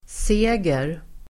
Uttal: [s'e:ger]